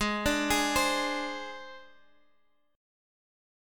AbMb5 chord